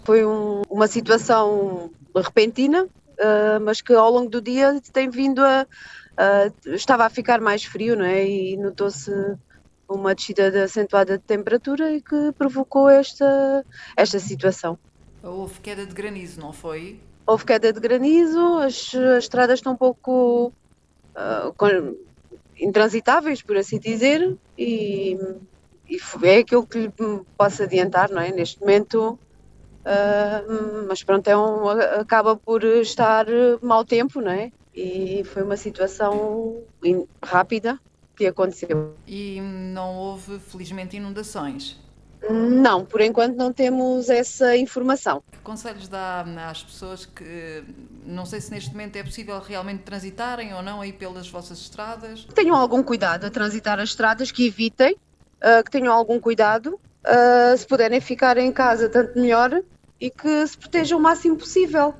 Oiça a entrevista da presidente da Junta de Freguesia de Avis.
Em declarações à TDS a presidente da Junta de Freguesia de Avis, Sílvia Feliz, relata a situação causada pela queda de granizo no concelho.